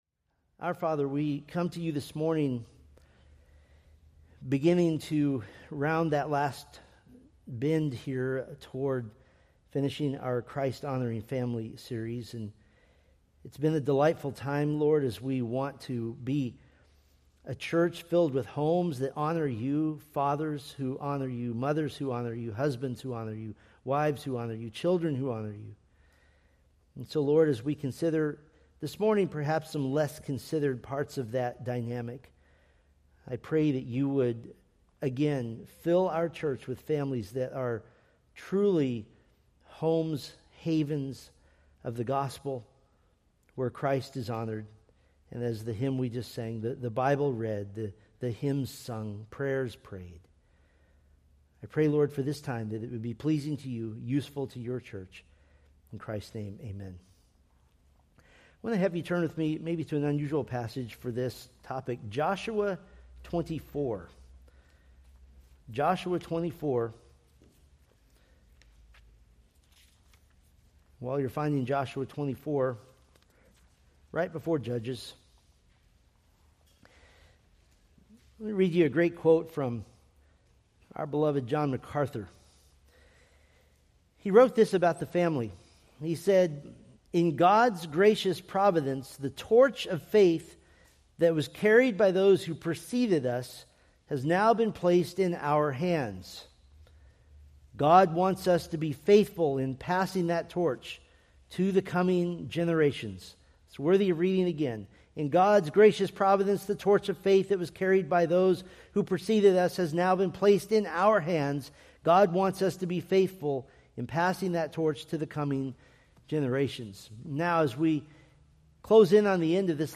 From the The Christ-Honoring Family sermon series.